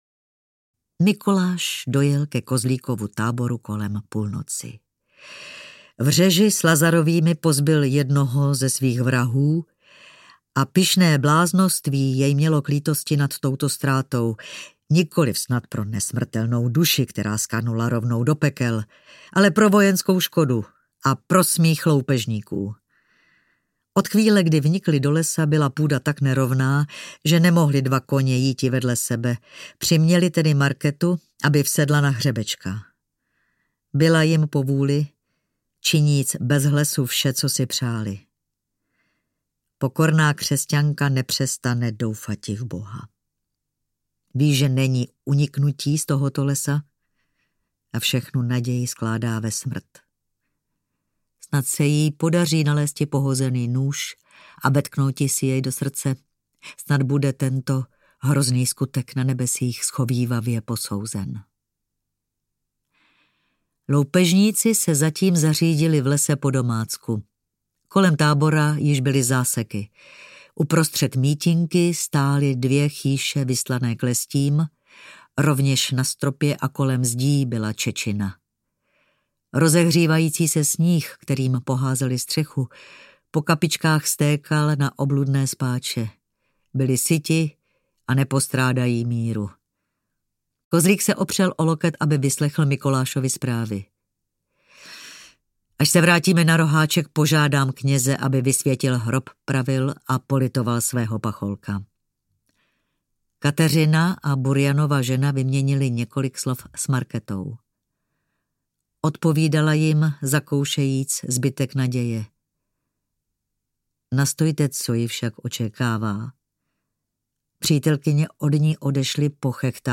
Markéta Lazarová audiokniha
Ukázka z knihy
Čte Milena Steinmasslová.
Hudba Jan Šikl.
Vyrobilo studio Soundguru.